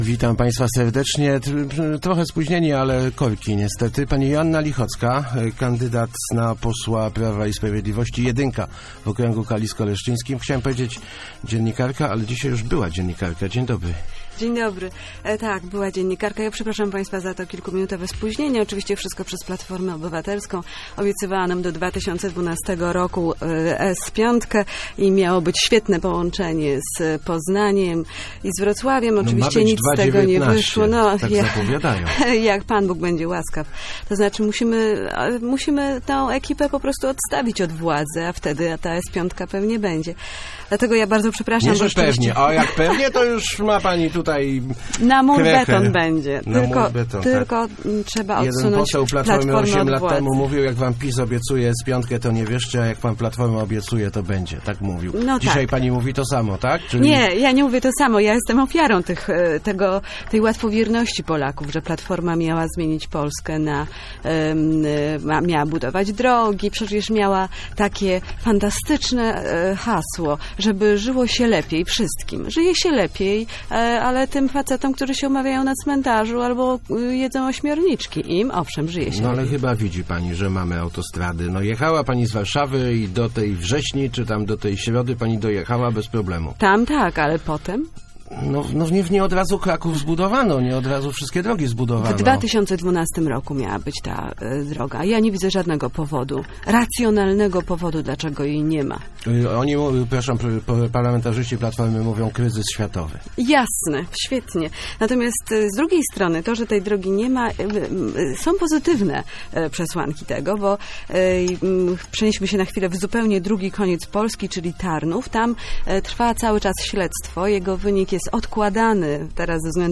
Wiem, że „spadochroniarze” nie mają tu łatwo – mówiła w Rozmowach Elki Joanna Lichocka.